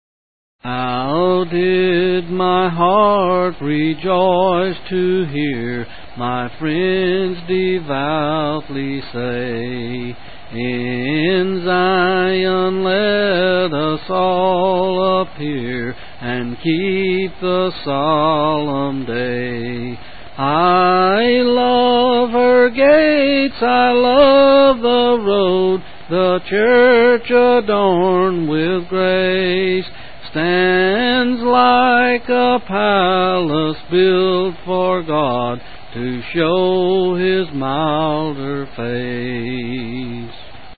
C. M